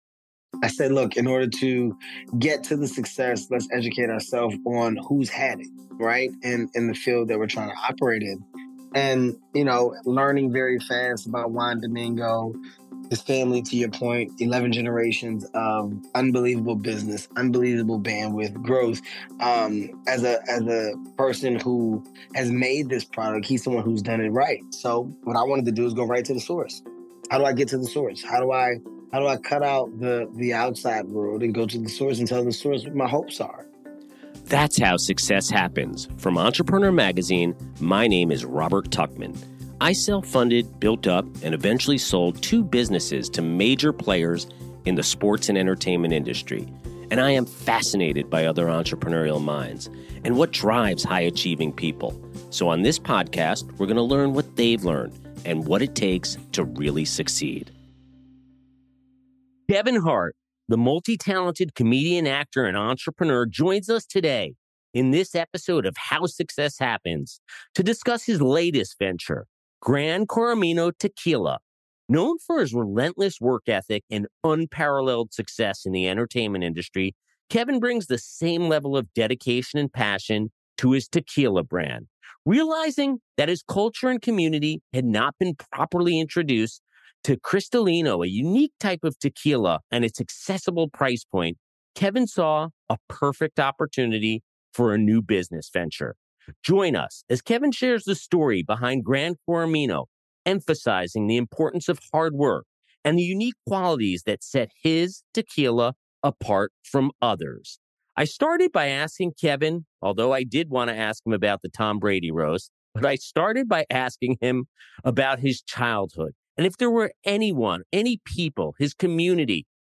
Kevin Hart, the multi-talented comedian, actor, and entrepreneur, joins us in this episode to discuss his latest venture: Gran Coramino Tequila.